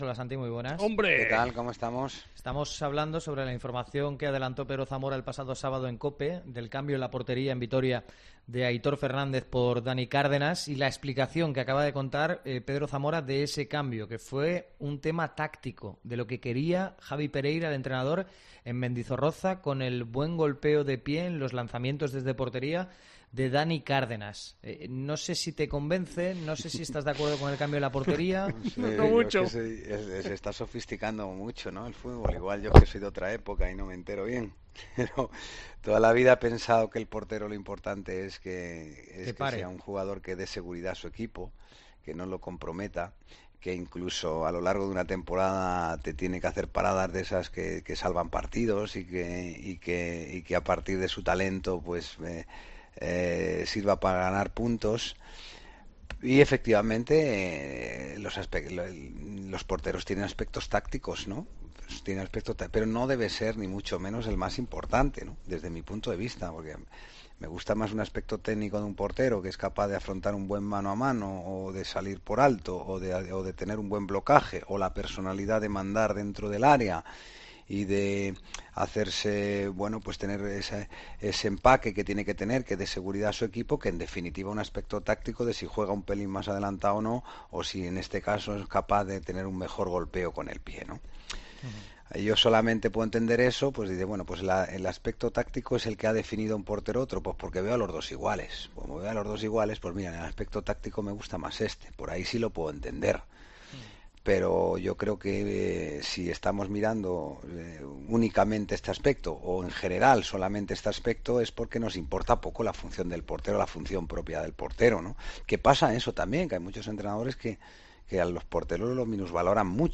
El ex guardameta del club asegura en COPE que ambos son más leyendas que cualquiera de su generación y carga contra la gestión del club